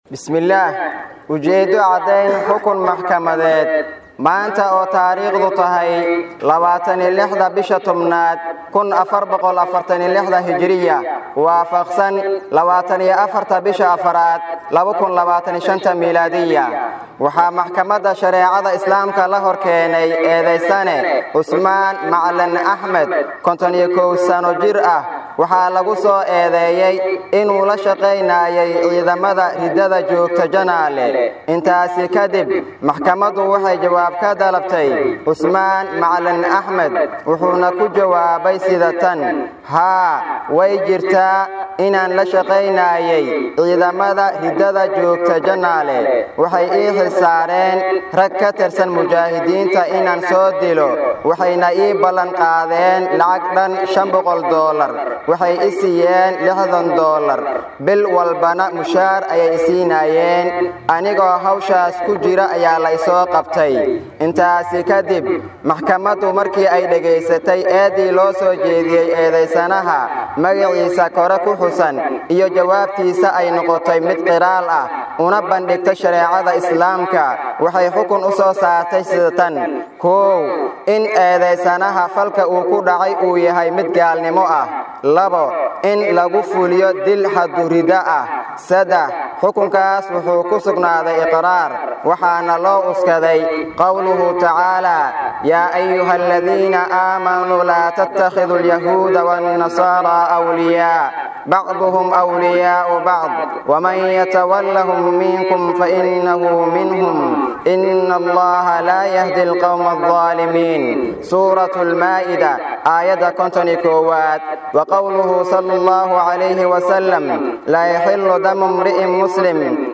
Fagaare ku yaala Kuunyo Barrow ayaa galabnimadii maanta waxaa isugu soo baxay boqollaal kamid ah dadka magaalada, kuwaasoo daawanayay xukunka Maxkamadda.
Mid kamid ah Qudaada Xarakada Mujaahidiinta Al-Shabaab ayaa fagaaraha ka akhriyay xukunka, ninka lagu fuliyay iyo maraajicda loo cuskaday intaba.
Halkaan ka Degso Codka Qaadiga